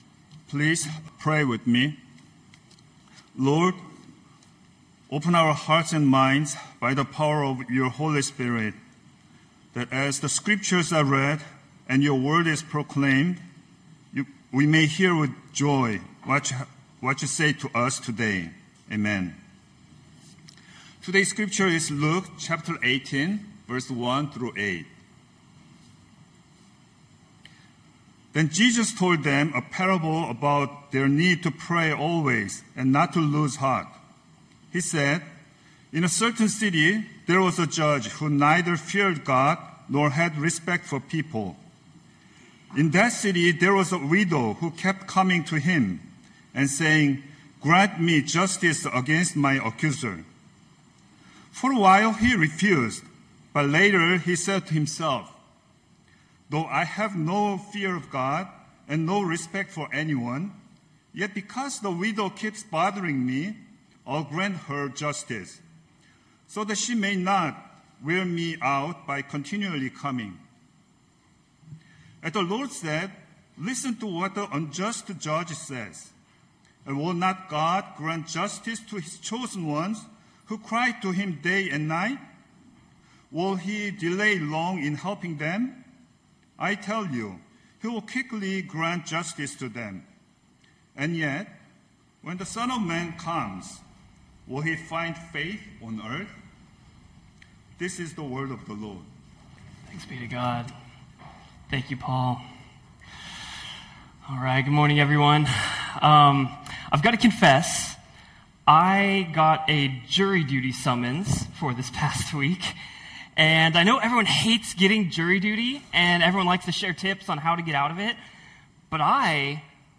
Knox Pasadena Sermons Persisting in Prayer Jun 22 2025 | 00:17:01 1x 00:00 / 00:17:01 Subscribe Share Spotify RSS Feed Share Link Embed